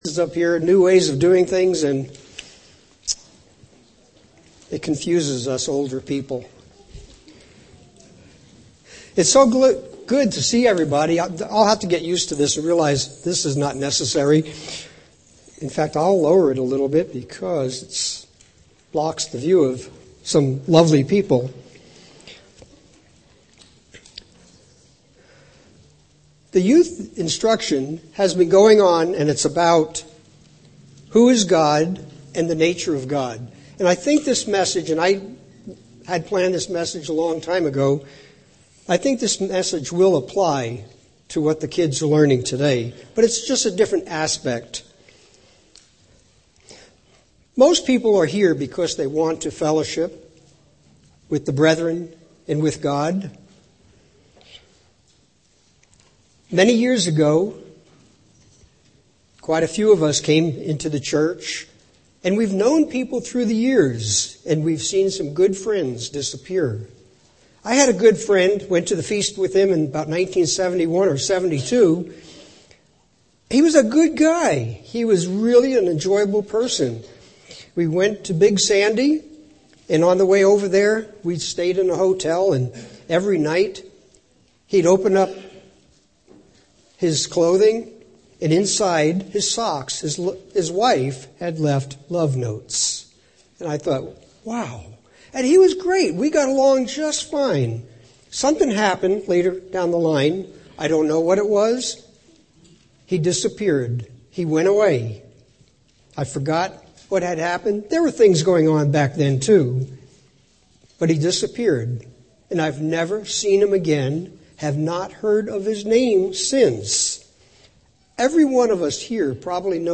Greetings brethren and guests!